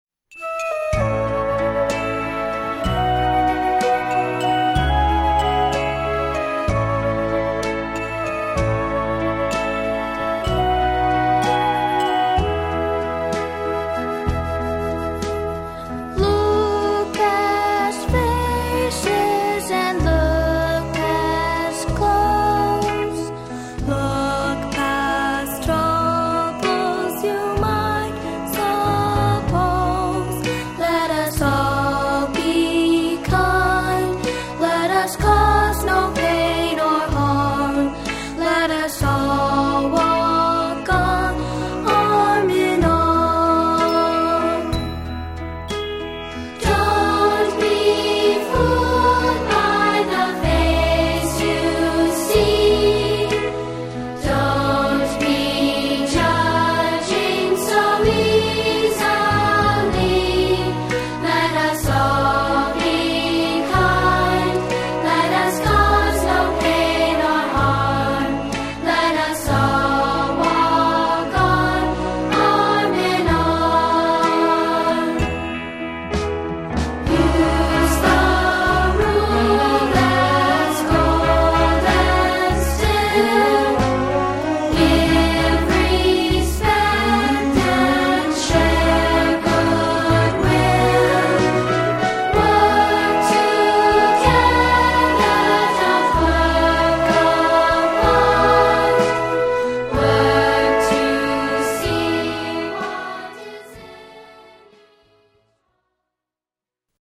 A Rockin’ Holiday Fantasy For Young Voices